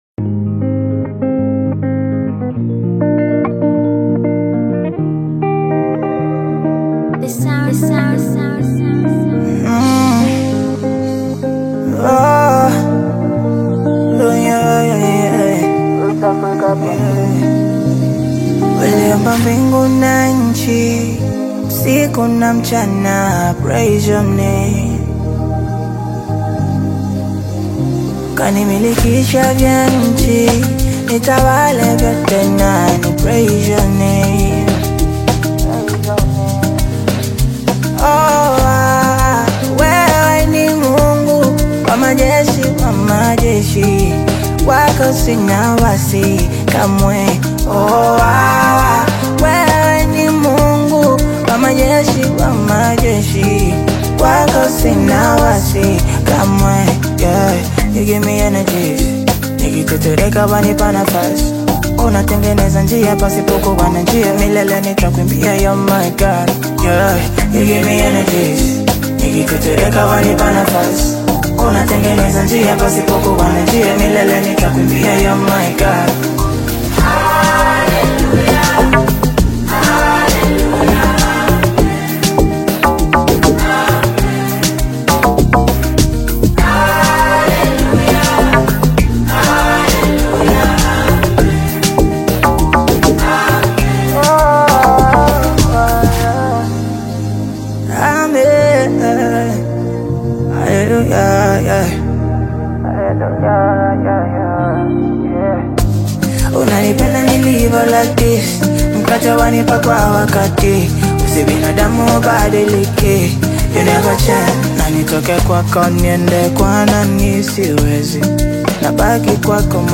A stirring anthem